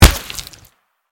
bullet_hit_2-converted.mp3